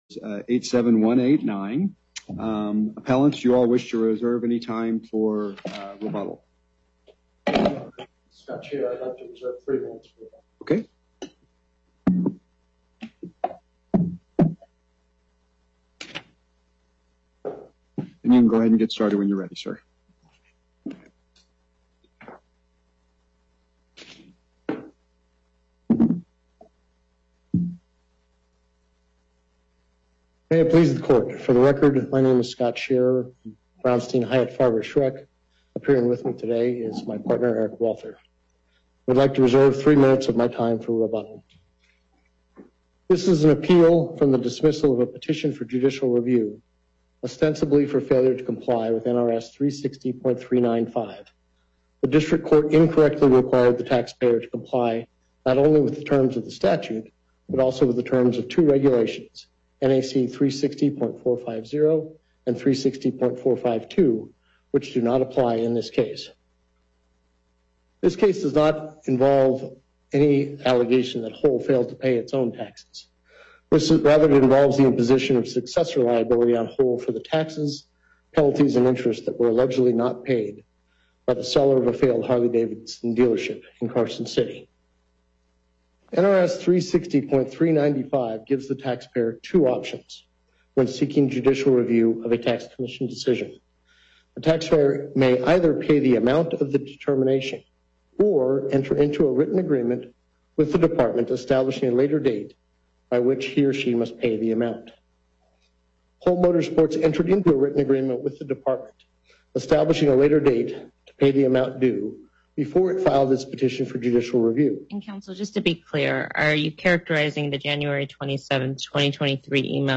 Before Panel A24, Justice Herndon presiding Appearances